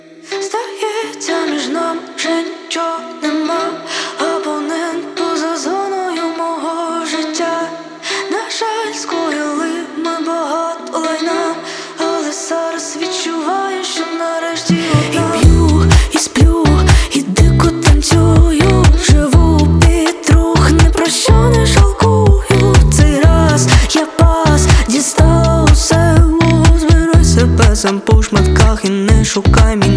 Жанр: Поп / Инди / Русские